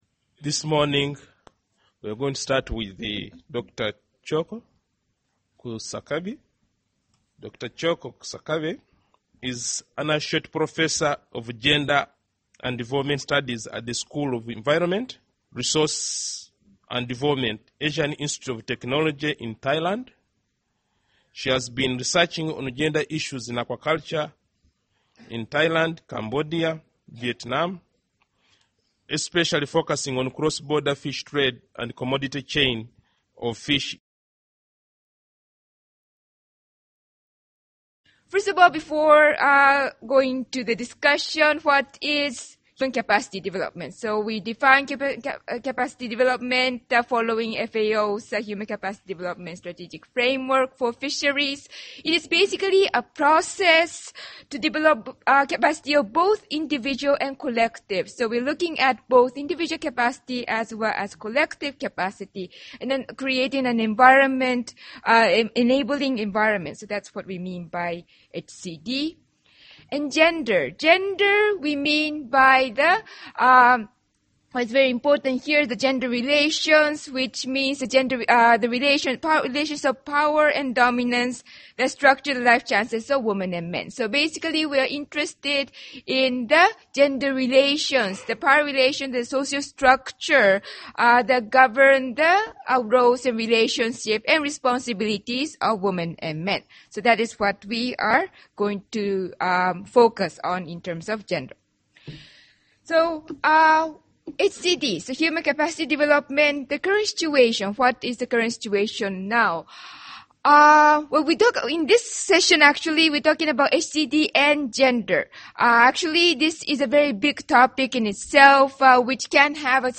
Presentation on addressing human capital and gender issues in aquaculture